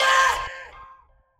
Vox (Culture).wav